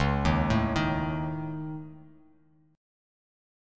C#sus2#5 Chord